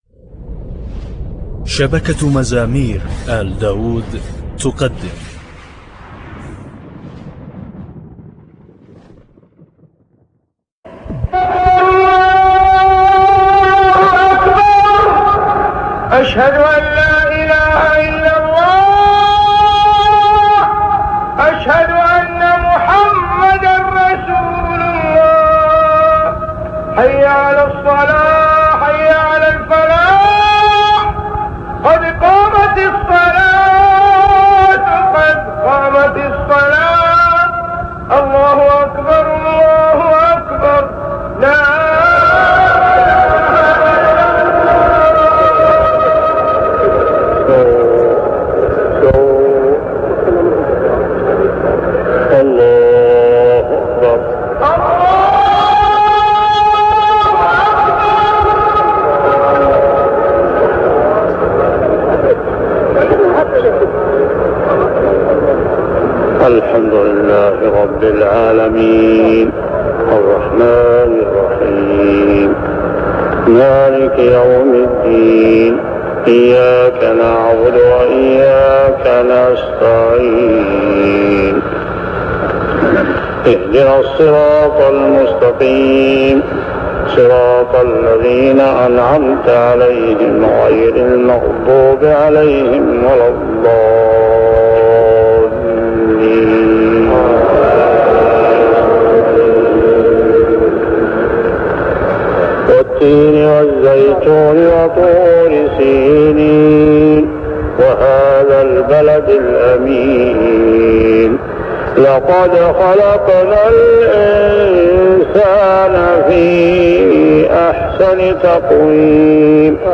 صلوات الفروض من الحرم المكي 1399هـ
صلاة المغرب (التين والقارعة) للشيخ الخليفي